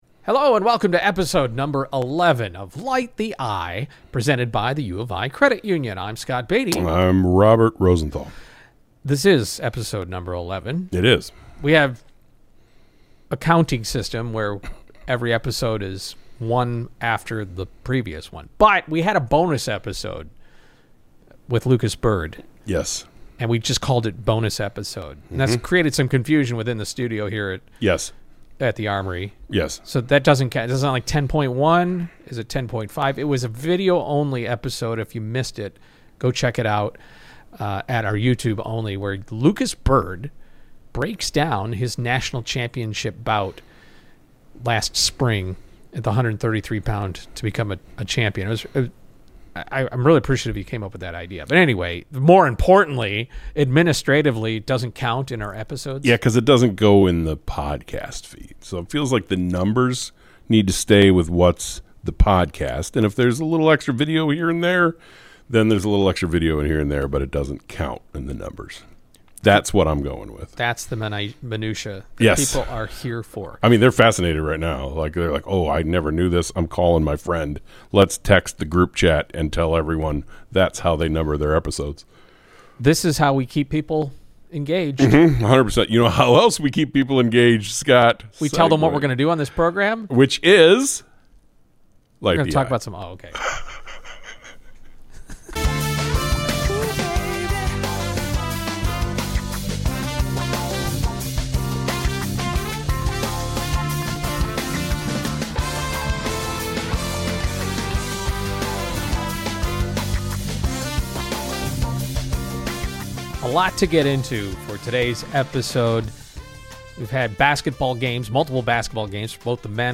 In this powerful episode, we sit down with a Retired Indian Navy Admiral to decode India’s rising stature through the eyes of someone who has lived strategy, command, and national se…